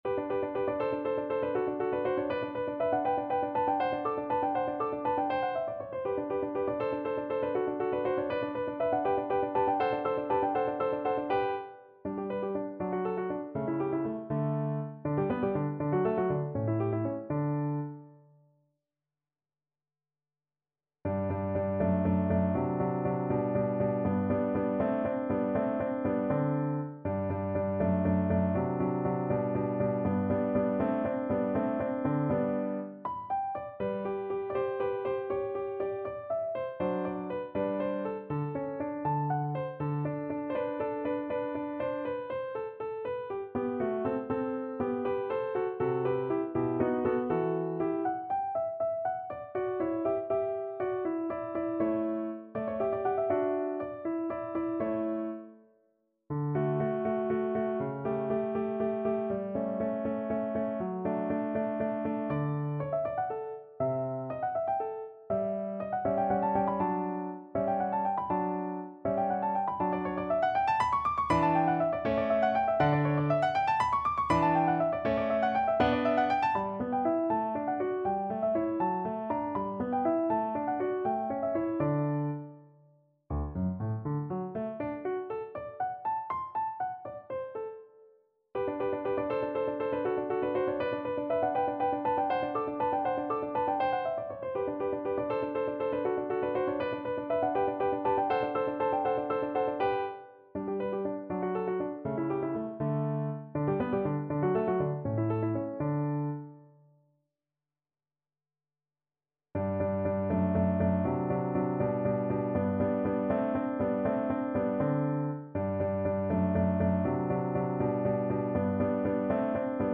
G major (Sounding Pitch) (View more G major Music for Violin )
6/8 (View more 6/8 Music)
~ = 100 Allegro (View more music marked Allegro)
Classical (View more Classical Violin Music)